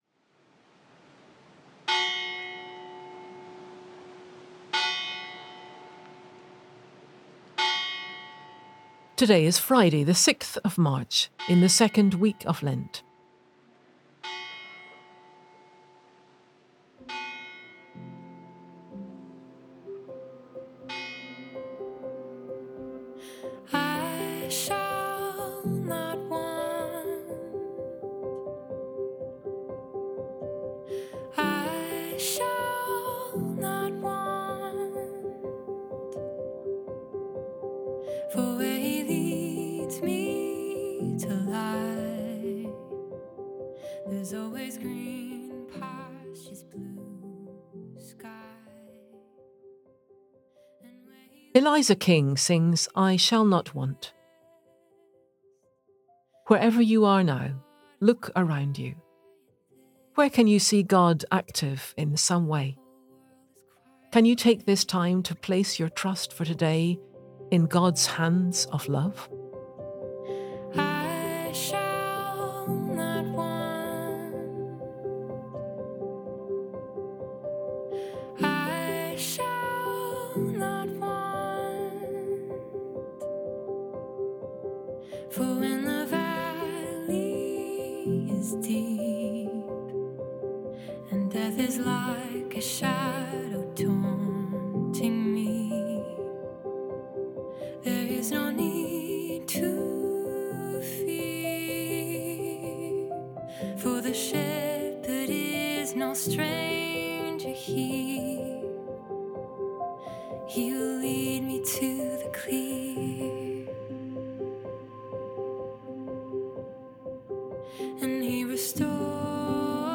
Pray As You Go - Daily Prayer Friday 6 March 2026 - Surrender and trust Play episode March 6 12 mins Bookmarks Episode Description Friday 6 March 2026 Today is Friday the 6th of March, in the second week of Lent.
Today’s reading is from the Gospel of Matthew.